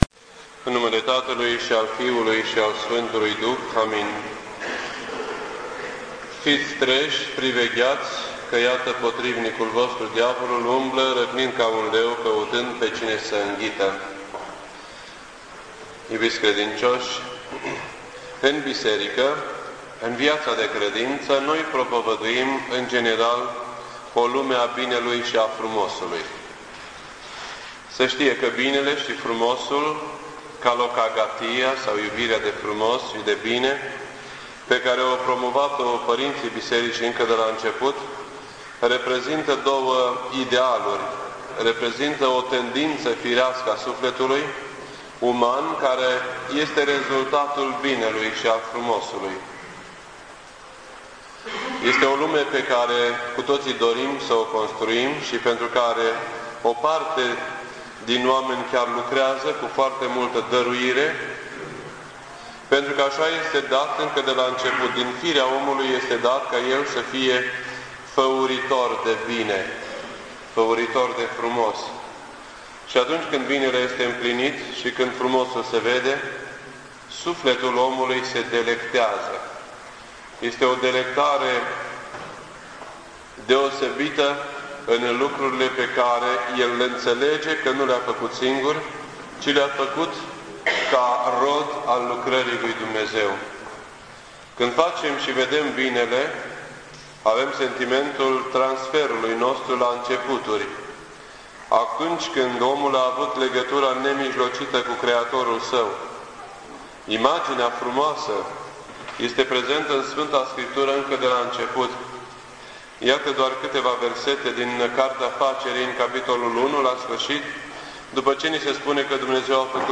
This entry was posted on Sunday, October 21st, 2007 at 10:05 AM and is filed under Predici ortodoxe in format audio.